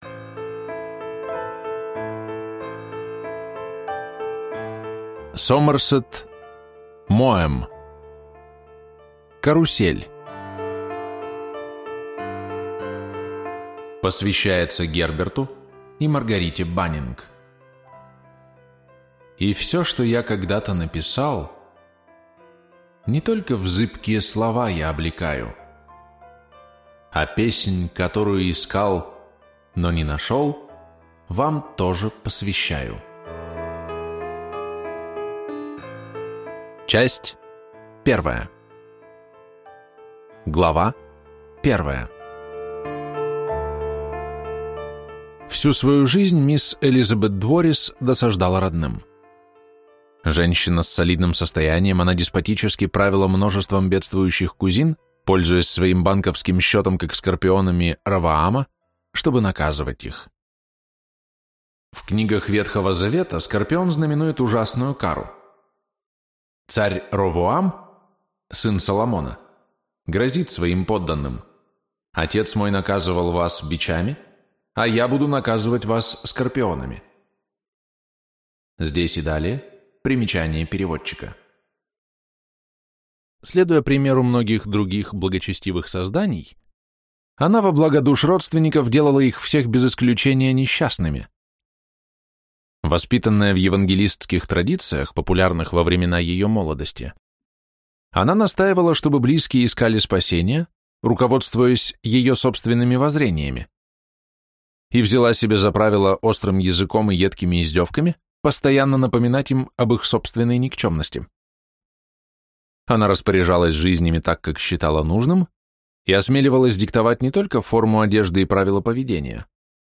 Аудиокнига Карусель | Библиотека аудиокниг